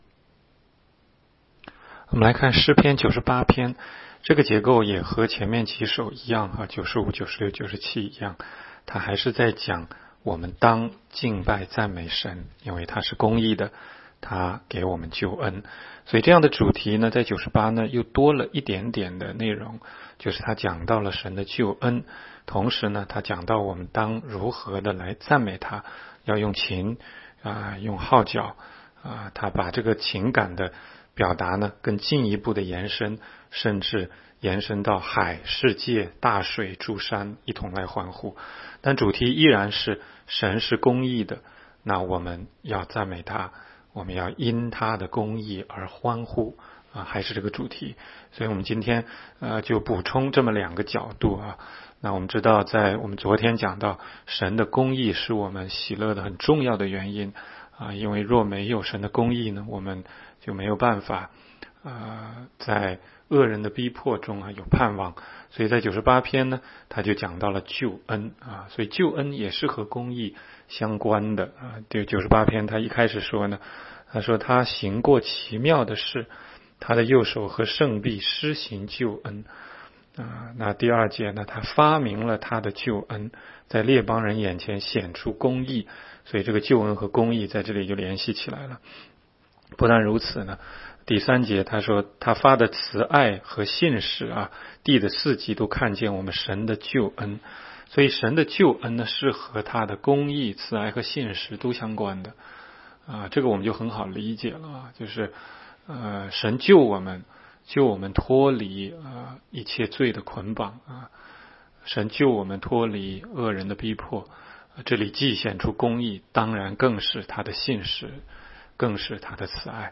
16街讲道录音 - 每日读经-《诗篇》98章